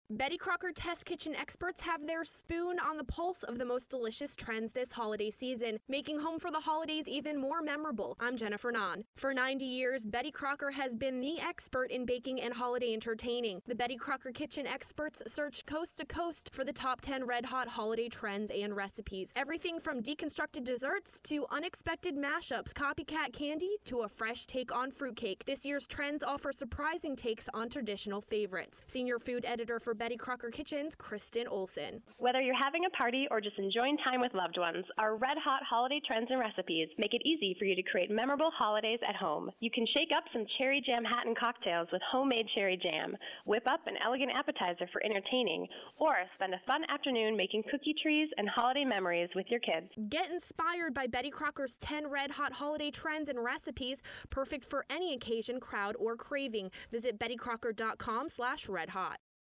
November 19, 2013Posted in: Audio News Release